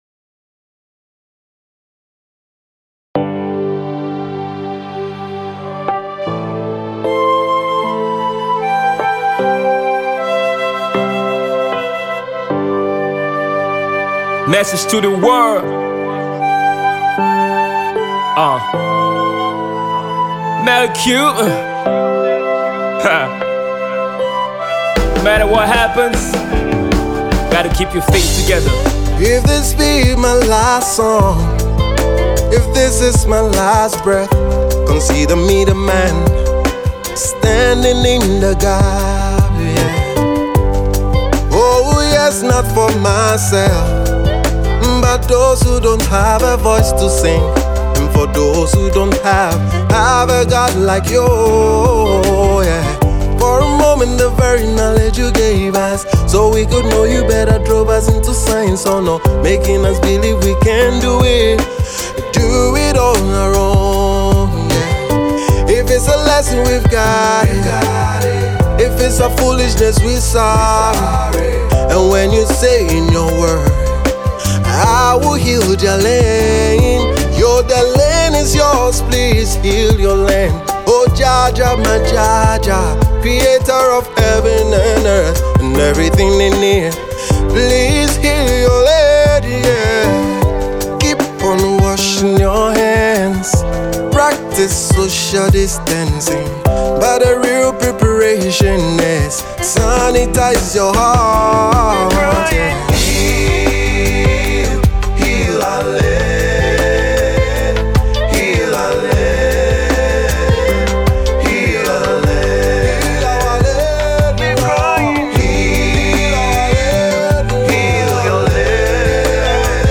rapper
vocalist
a soul touching tune